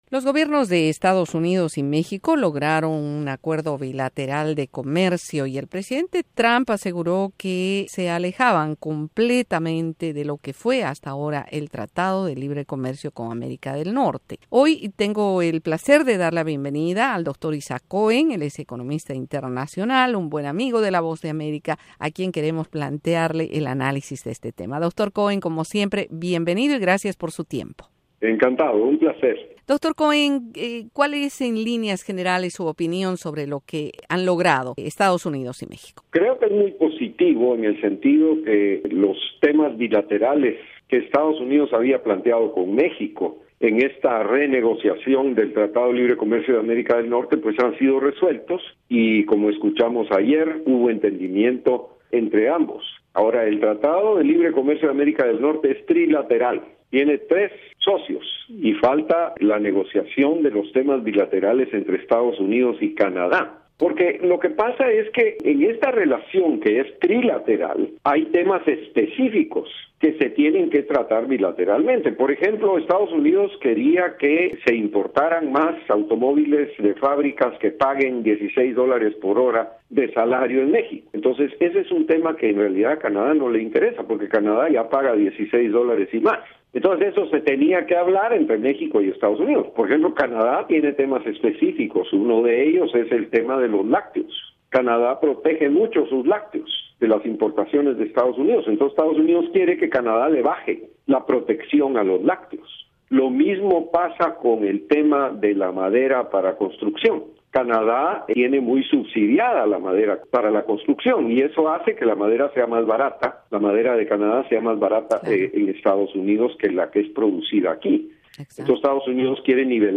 El tema se plantea en análisis en esta entrevista de la Voz de América